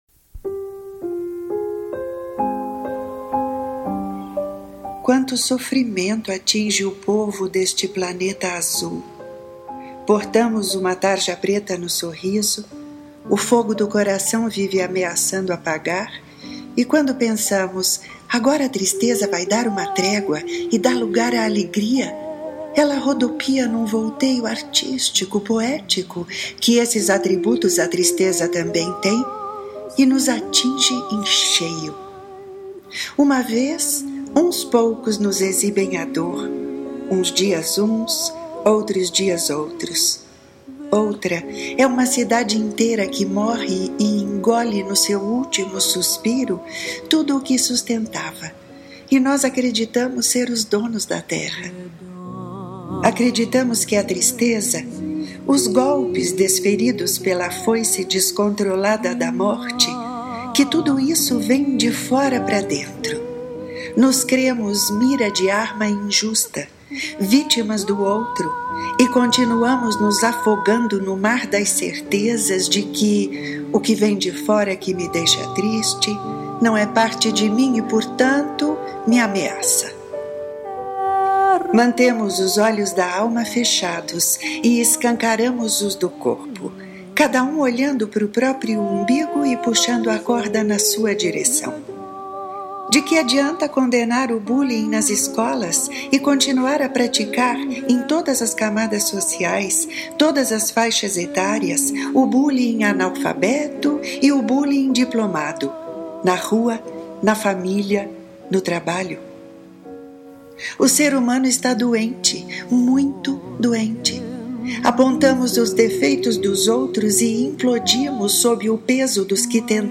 Ouça ‘De olhos da alma’ na voz e sonorizado pela autora